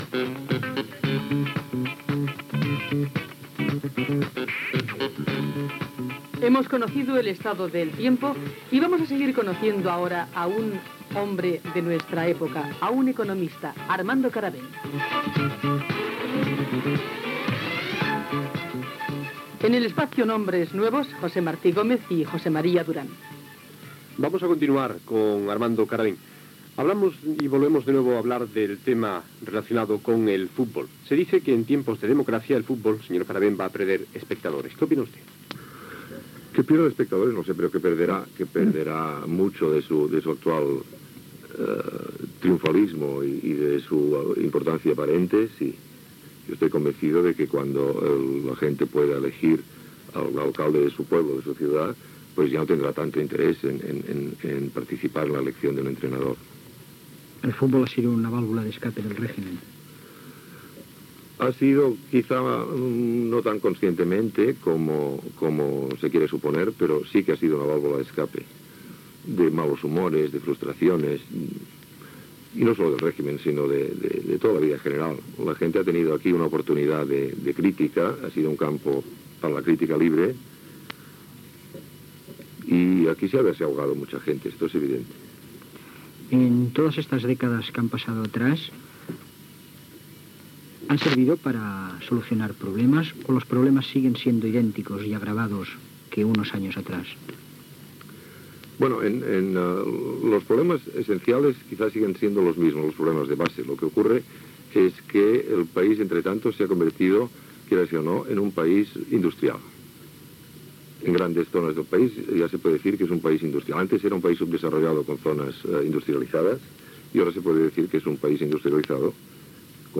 Presentació.
Hora i tema musical
Informatiu